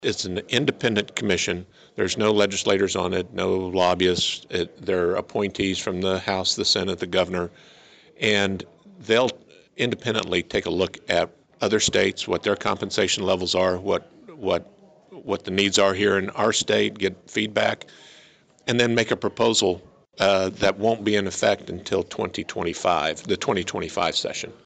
With the end of the 2023 regular legislative session approaching, local lawmakers were able to offer updates on a variety of topics during the latest legislative dialogue at the Flint Hills Technical College main campus Saturday morning.